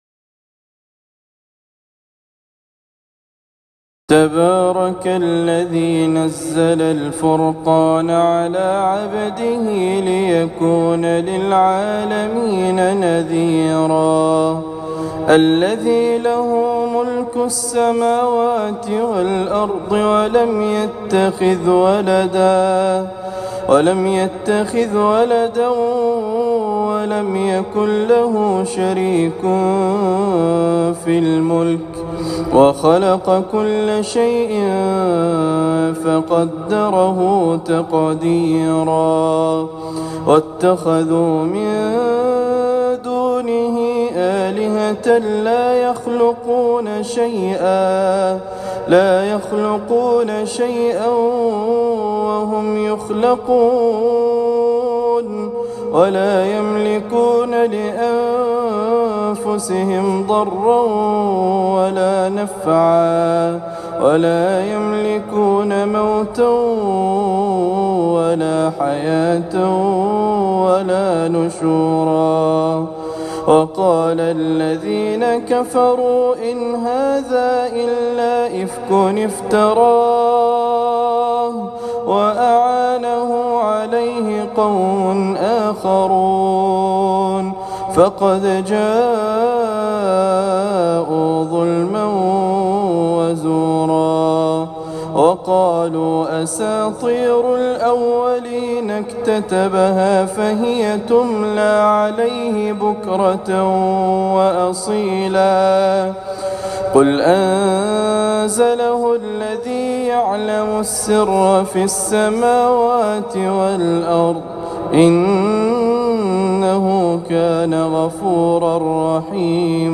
سورة الفرقان من ليالي رمضان 1439